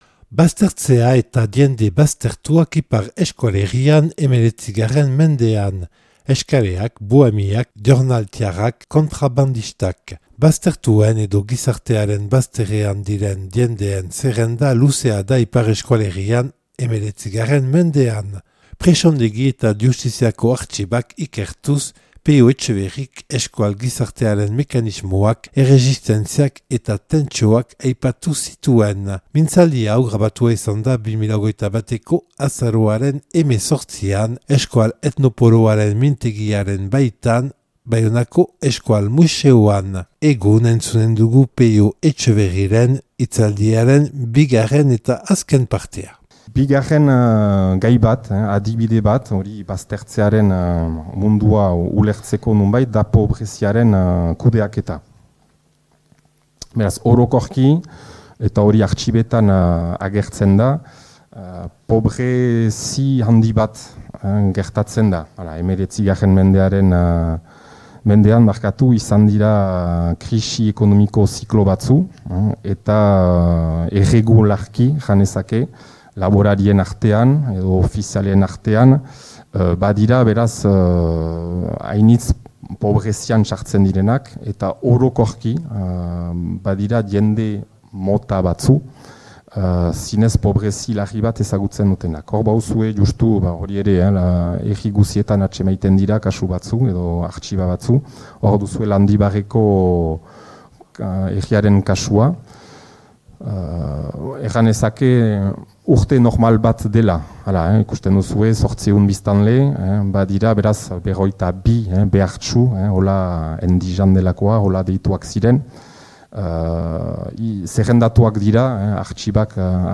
Euskal etnopoloaren mintegiaren baitan